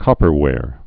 (kŏpər-wâr)